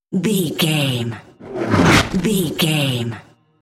Whoosh fast airy cinematic
Sound Effects
Fast
futuristic
tension
whoosh